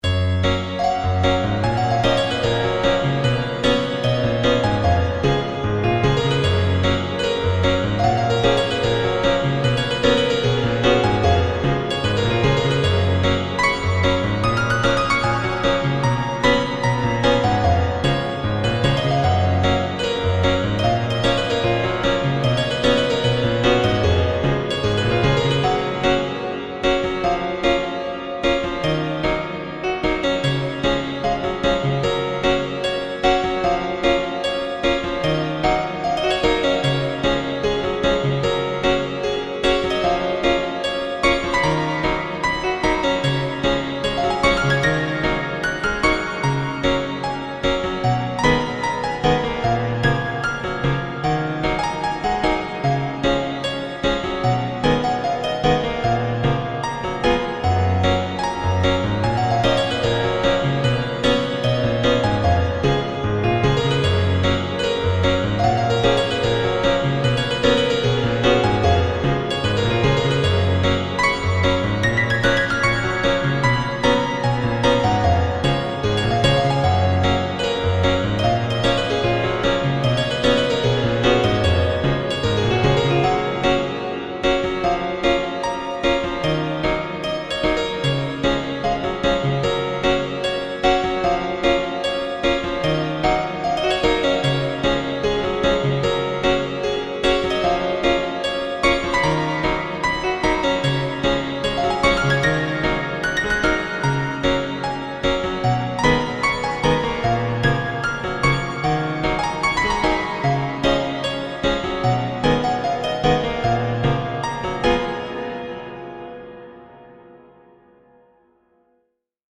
And no, I did not play this IRL, I composed it via DAW.
oh its not bad, kinda classical ig? its smooth, buh in da beginnin it just needs a tiny, tiny bit of tuning, good job doe.
piano